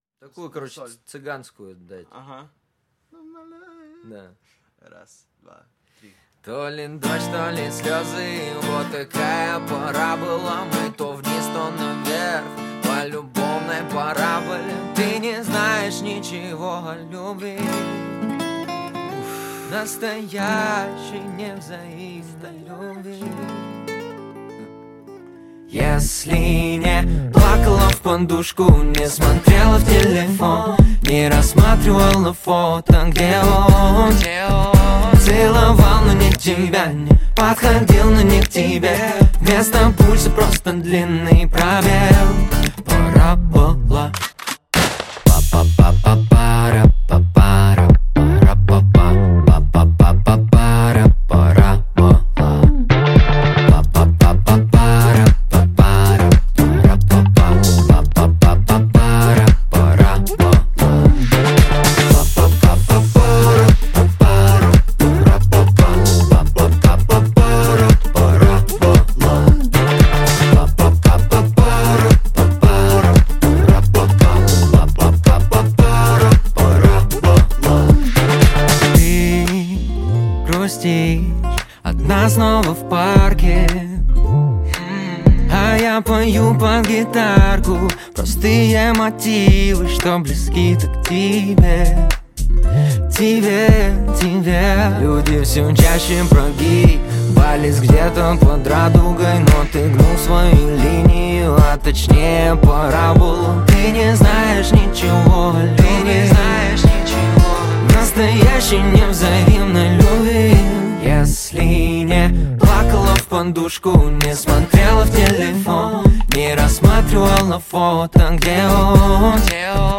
Русский рэп
Жанр: Русский рэп / R & B